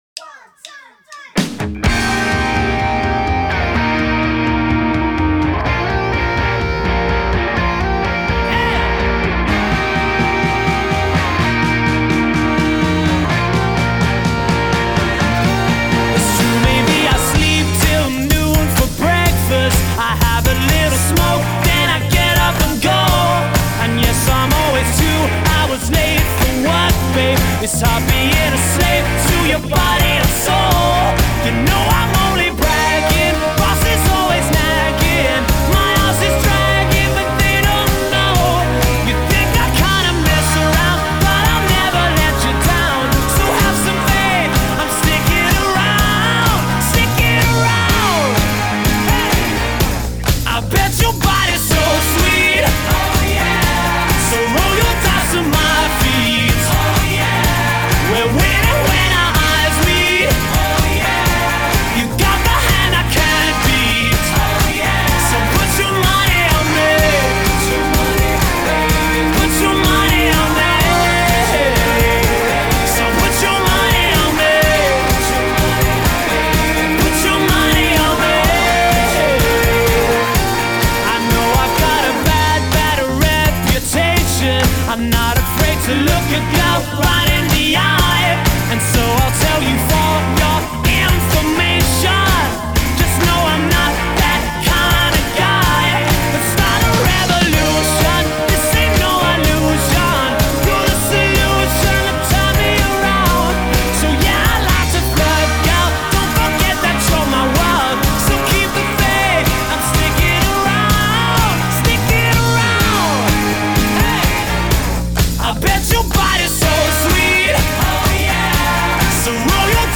Genre: Rock, Indie Rock